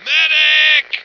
flak_m/sounds/male2/int/M2Medic.ogg at ac4c53b3efc011c6eda803d9c1f26cd622afffce